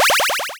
upgrade.wav